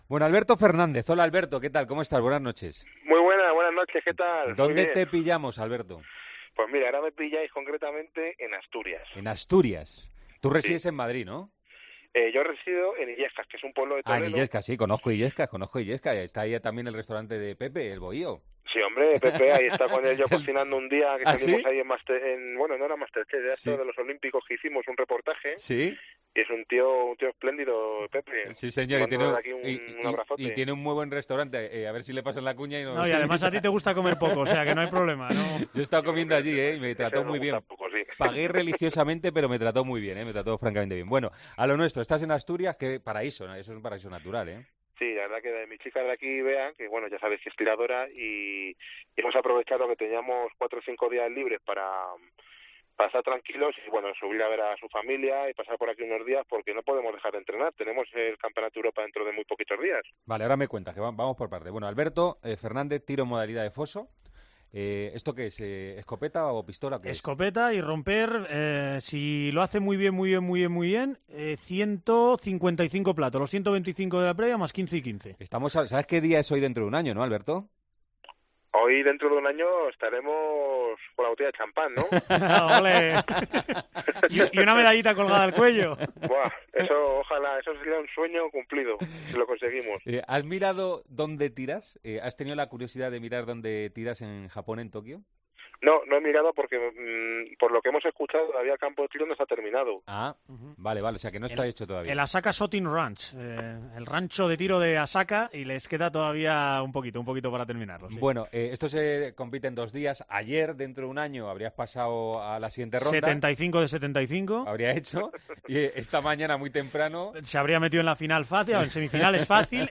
AUDIO: Hablamos con el doble campeón del mundo de tiro al plato, que en Tokio espera que a la cuarta sea la vencida y pueda conseguir su primer metal...